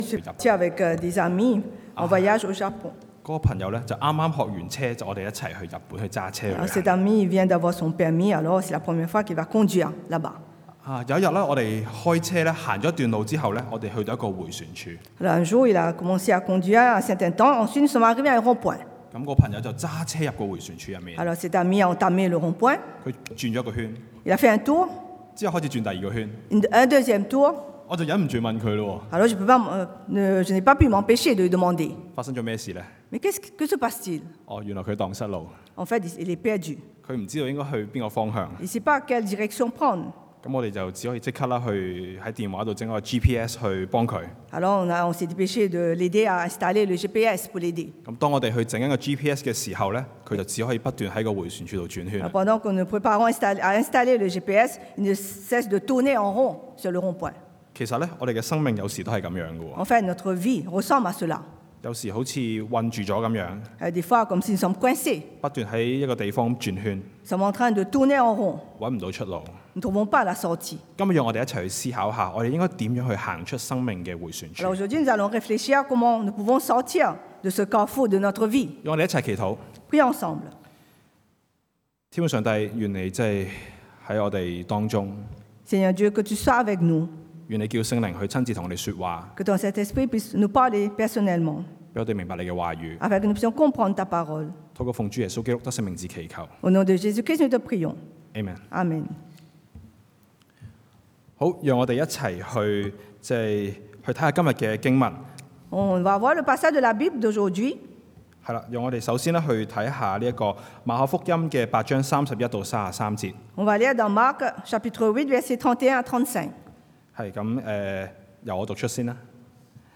31-35 Type De Service: Predication du dimanche « Sur la montagne